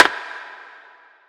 Perc 12 [ wood ].wav